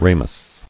[ra·mous]